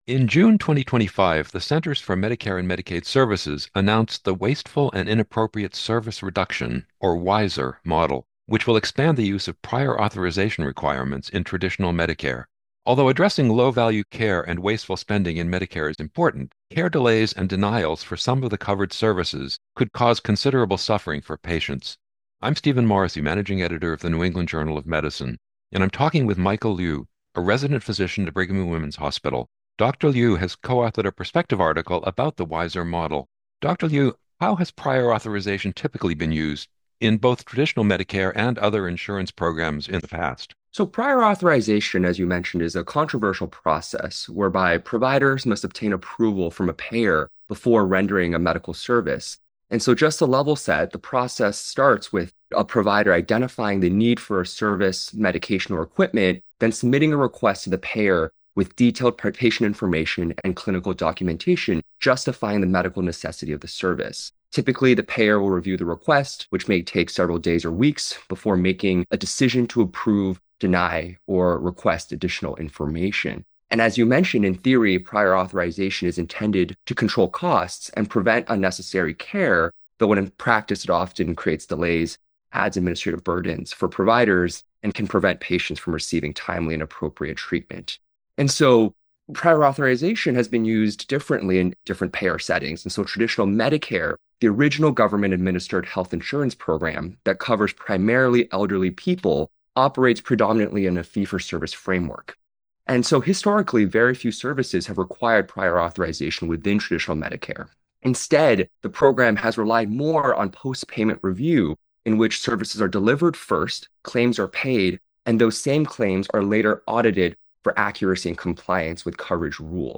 NEJM Interview